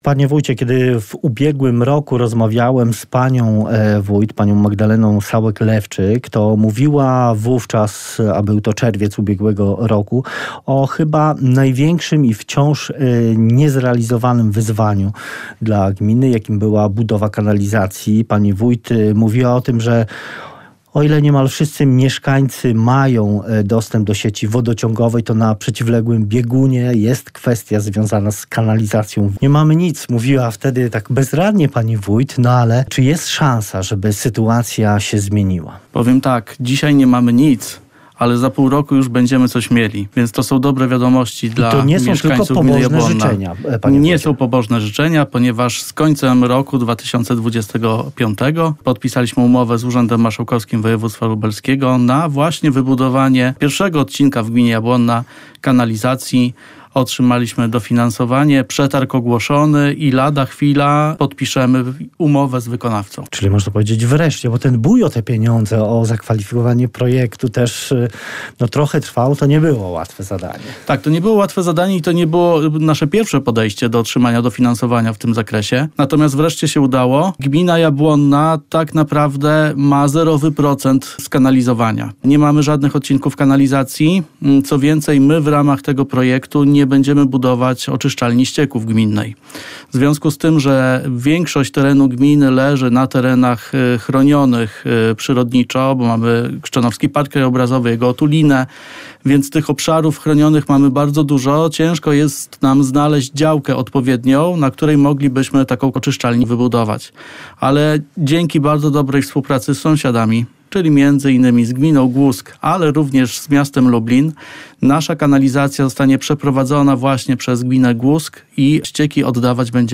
Rozmowa z zastępcą wójta gminy Jabłonna Marcinem Pastuszakiem